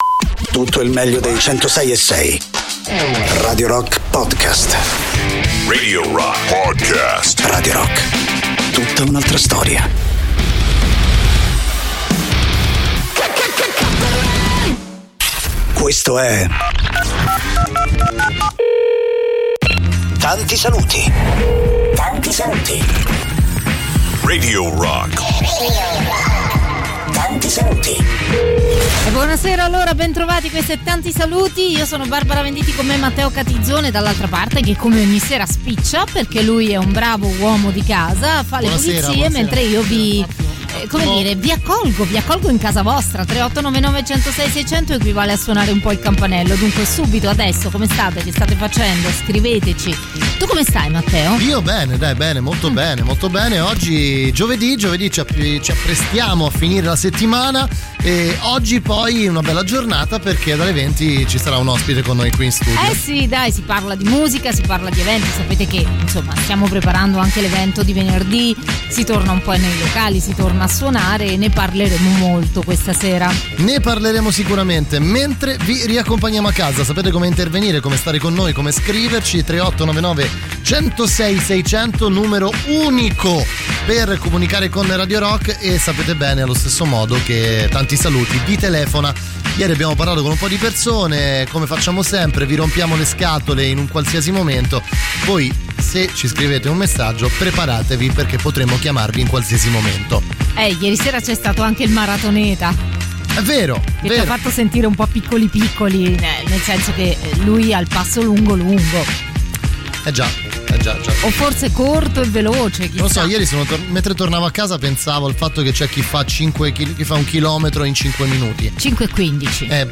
in diretta